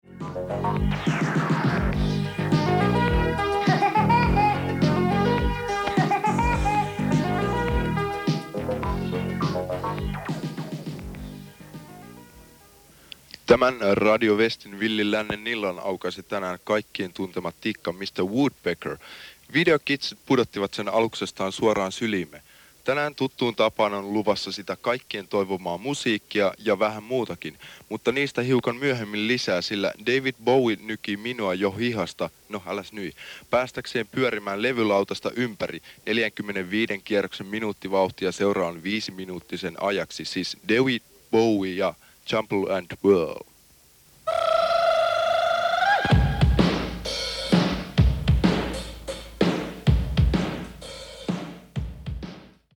Radio Westin iltalähetys Villin lännen ilta alkaa lokakuussa 1985.
Radio-West-Villin-lannen-ilta-aloitus-1985.mp3